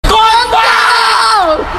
Suara meme Teriak Kontol Rame-rame (Awkarin)
Kategori: Suara viral
suara-meme-teriak-kontol-rame-rame-awkarin-id-www_tiengdong_com.mp3